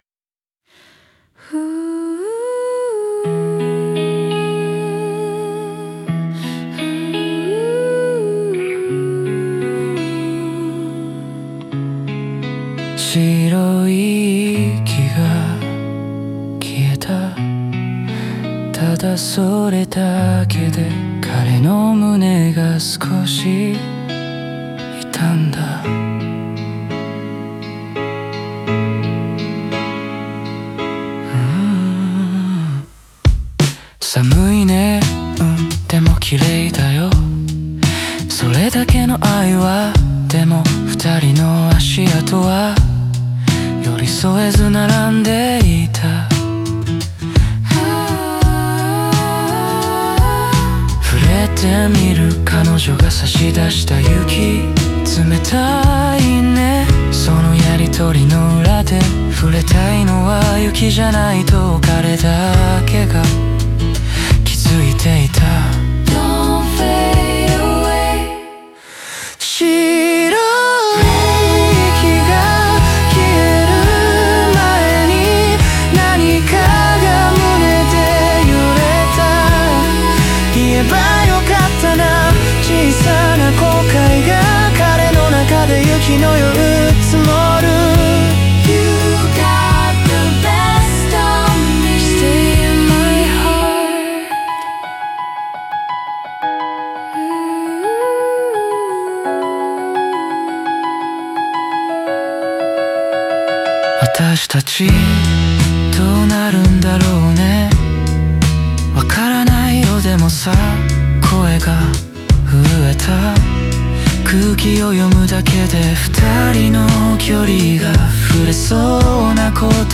曲は徐々に盛り上がり、最後のクライマックスで感情のピークが訪れる流れが、未練や余韻、記憶の温度を自然に感じさせる。